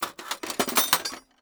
TOOL_Toolbox_Handle_RR2_mono.wav